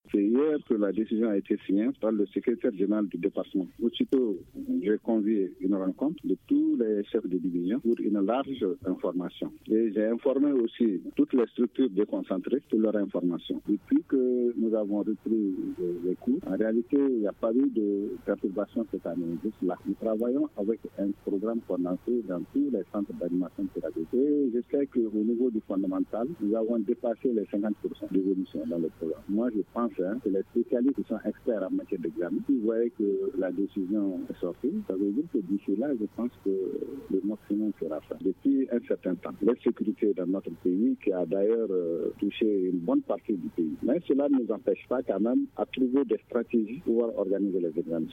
joint au téléphone